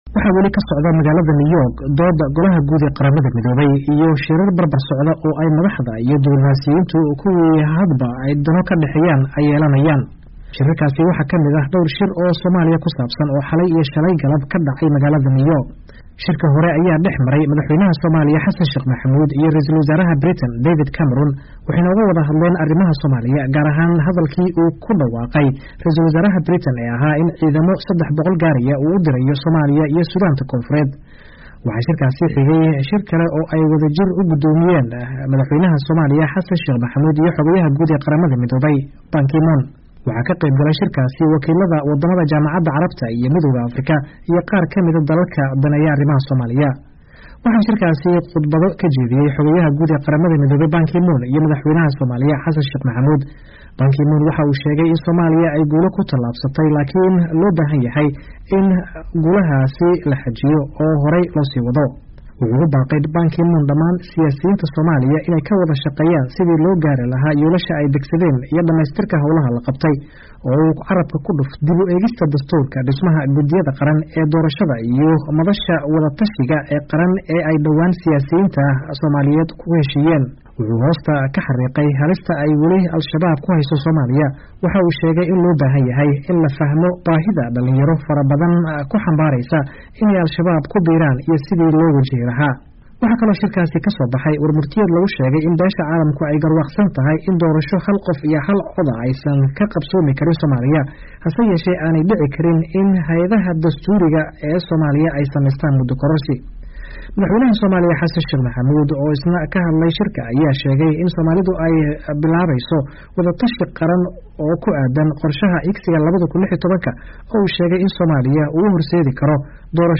Warbixin: Kullankii Somaaliya & Caalamka